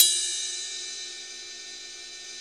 CYM XCHEEZ09.wav